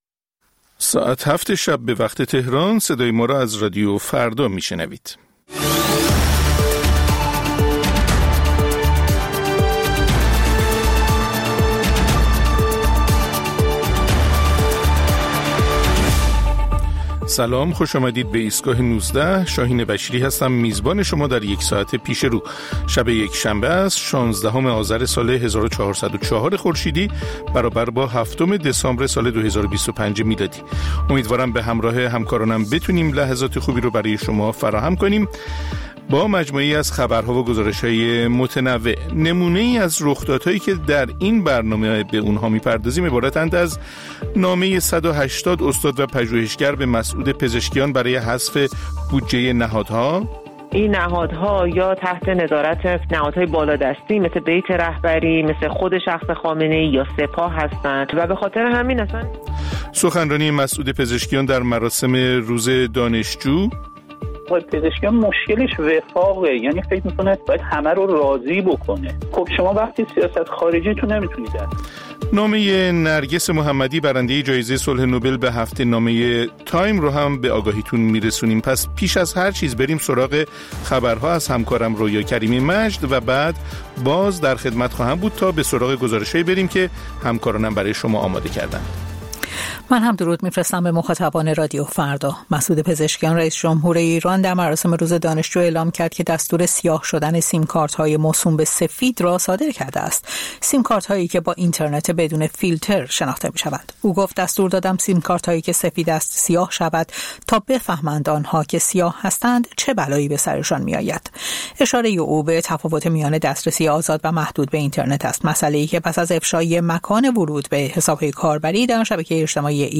مجموعه‌ای از اخبار، گزارش‌ها و گفت‌وگوها در ایستگاه ۱۹ رادیو فردا